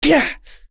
Kasel-Vox_Damage_01.wav